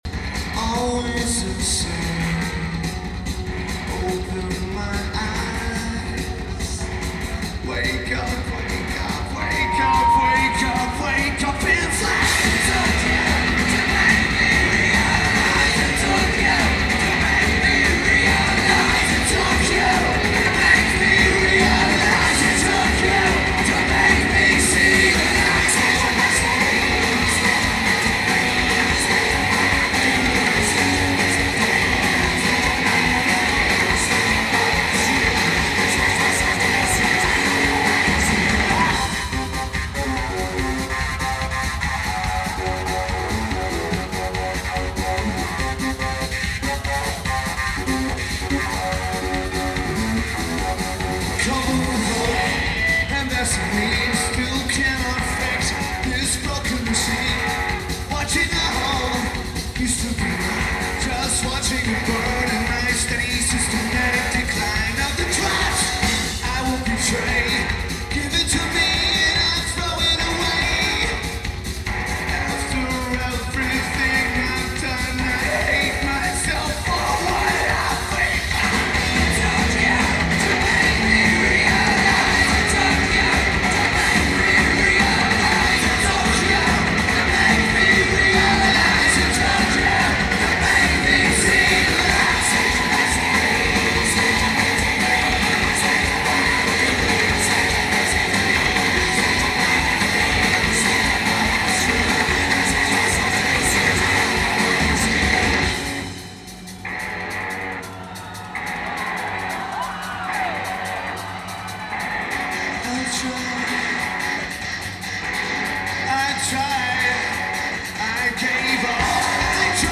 Alltel Pavilion
Lineage: Audio - AUD (CSC's + BB + Sony PCM-M1)